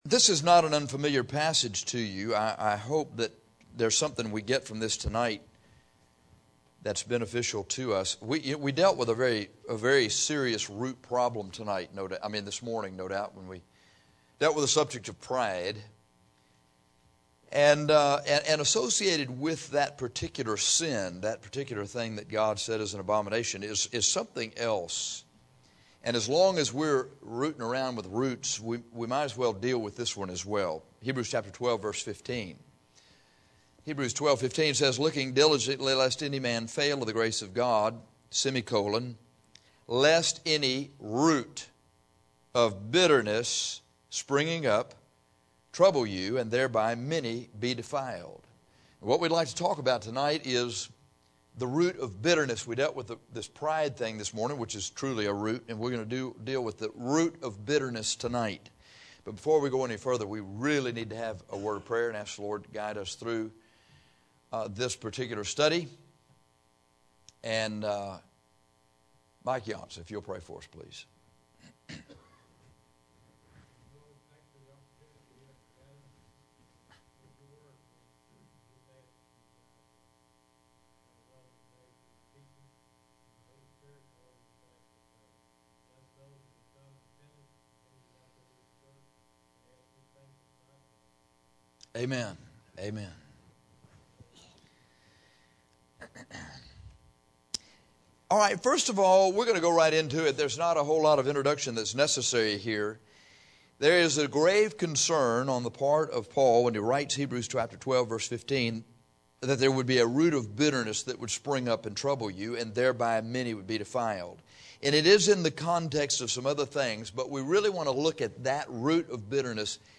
In this sermon on Heb 12:15, we get right down to the root of bitterness.